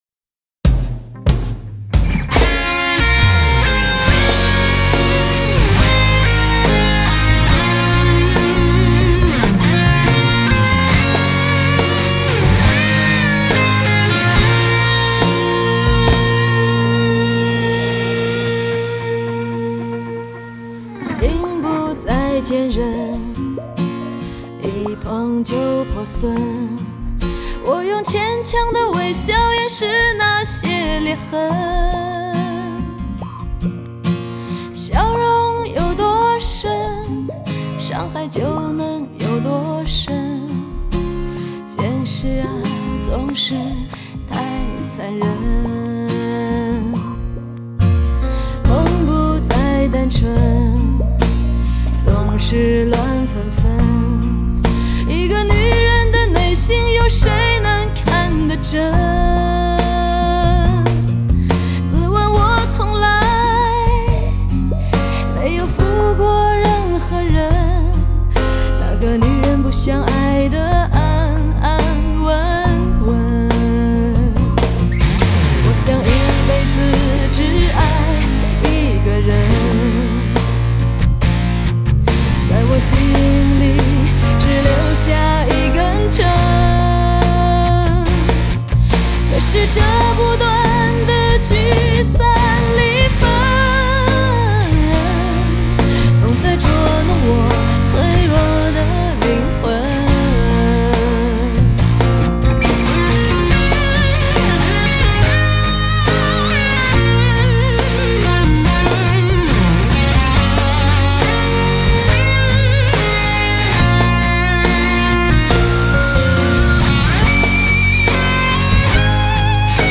卷起全亚洲最寂寞的声音